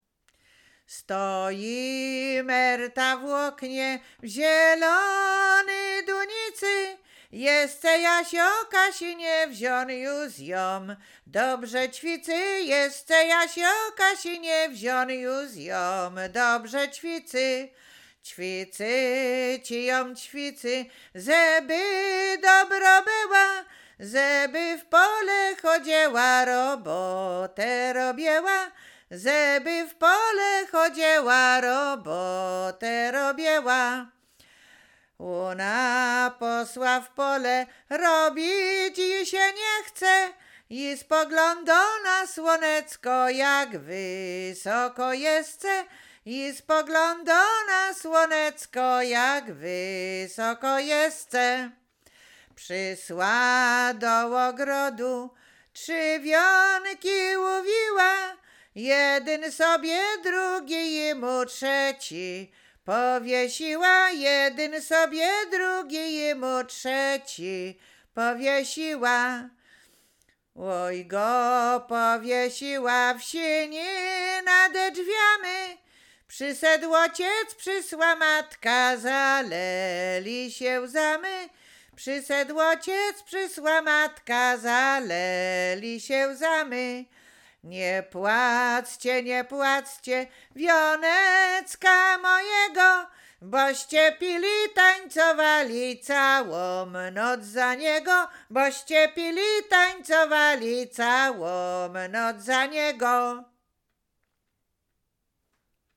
Łowickie
wesele weselne miłosne liryczne wianek